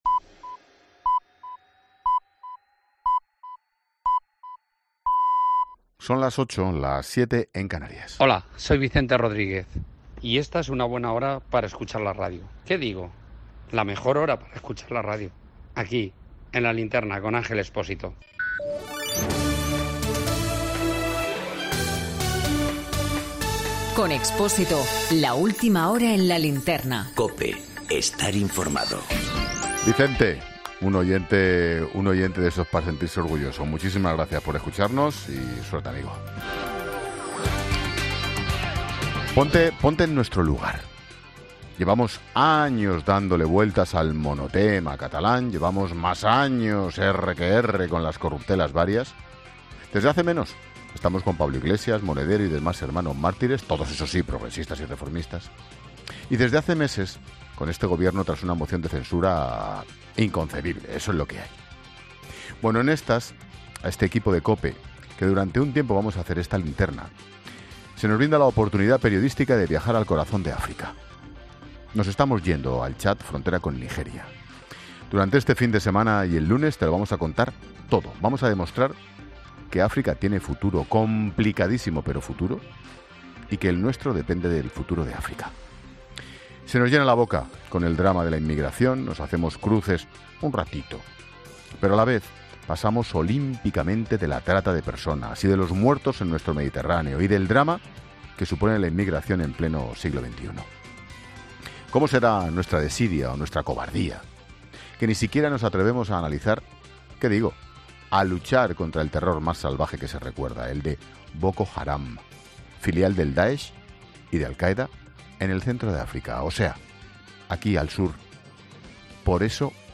Monólogo de Expósito
Ángel Expósito en el estudio de la Cadena COPE